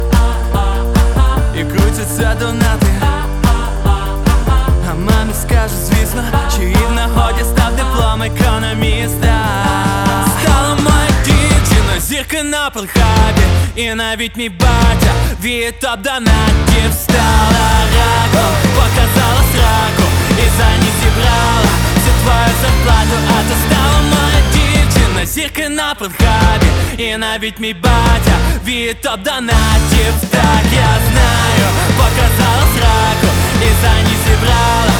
Жанр: Рок / Украинский рок / Украинские